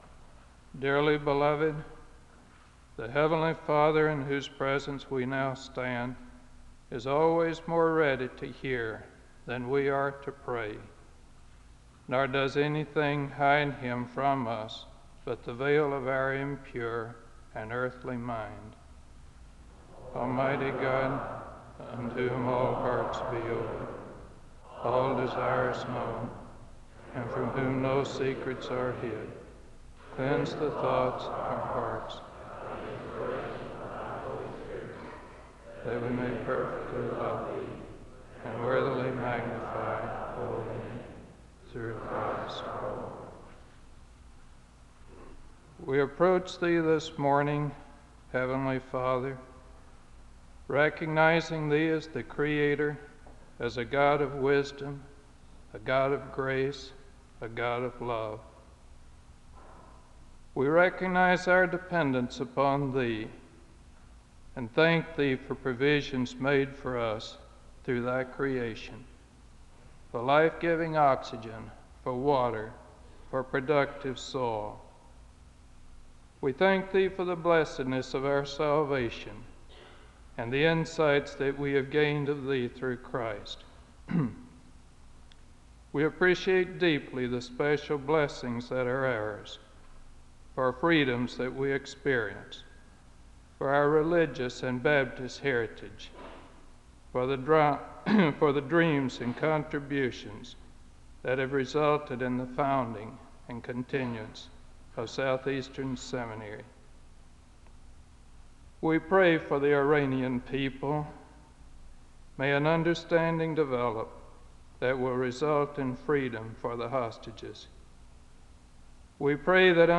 The service opens with a word of prayer (00:00-03:04). The choir leads in a song of worship (03:05-07:09).
Thanksgiving Day sermons